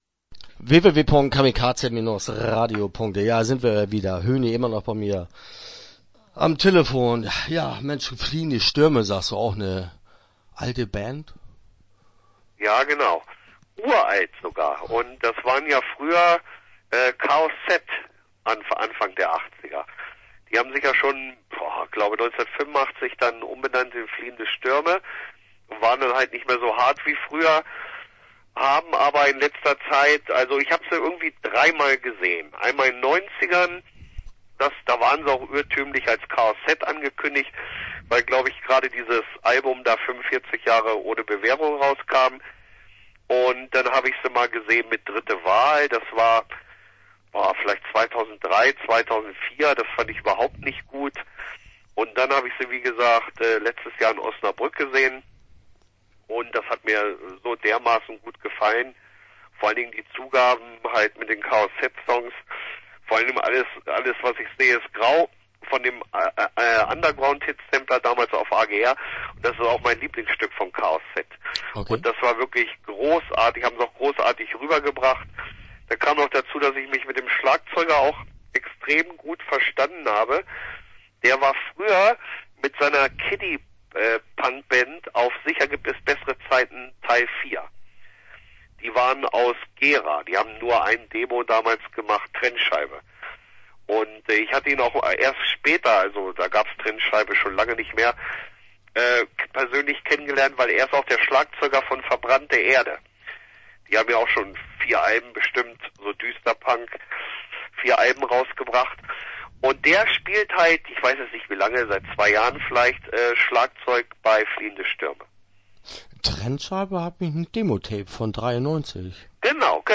Interview Teil 1 (8:53)